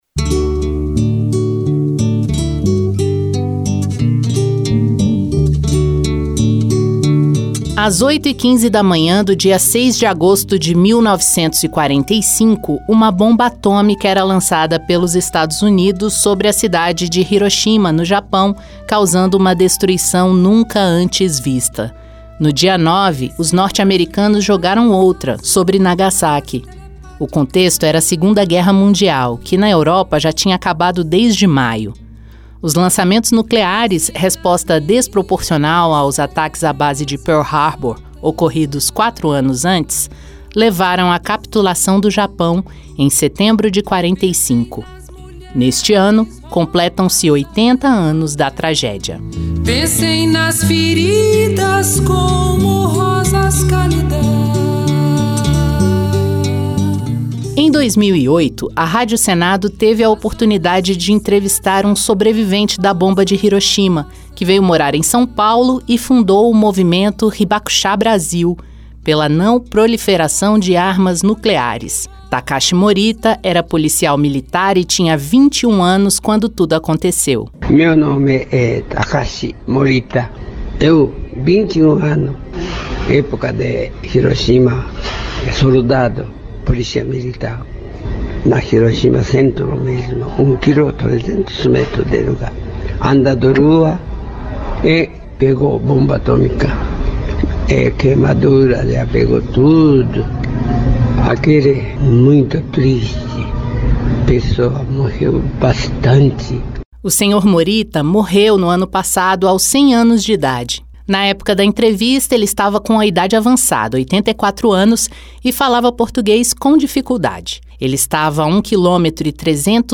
Trilha Sonora